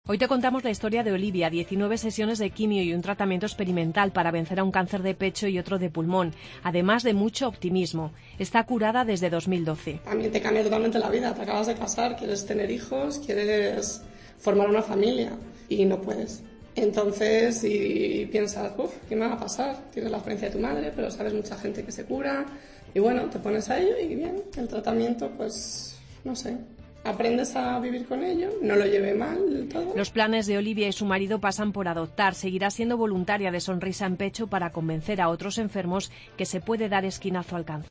AUDIO: Día Mundial contra el cáncer. Reportaje